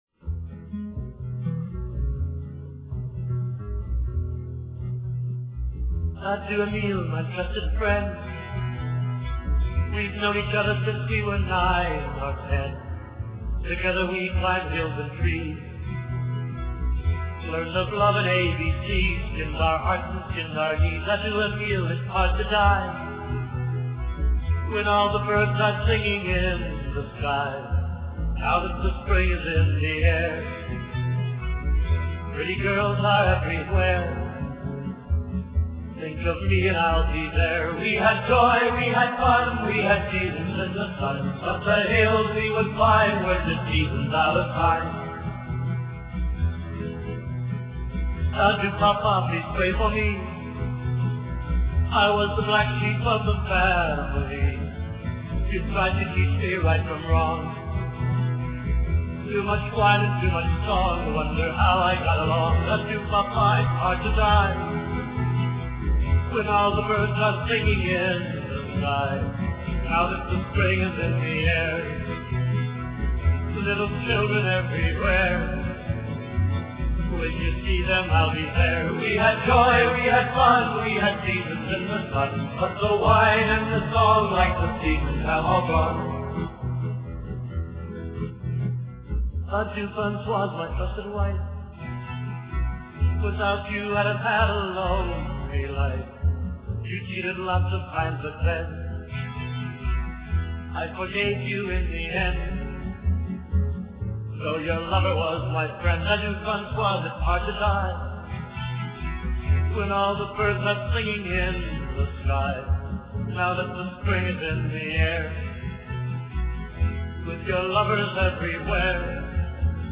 Seasons in the Sun (song)